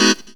HOUSE 2-L.wav